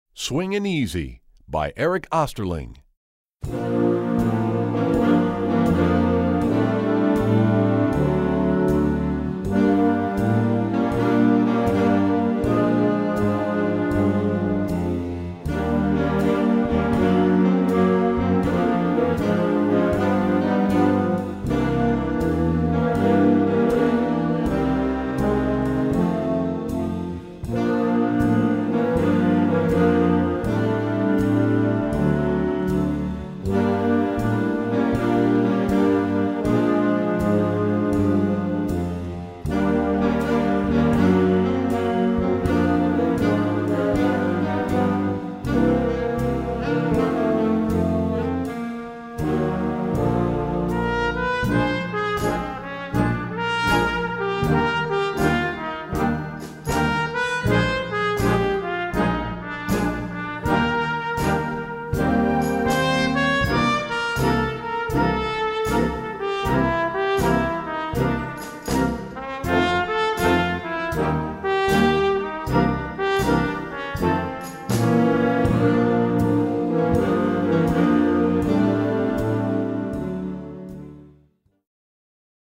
Besetzung: Blasorchester
medium swing tune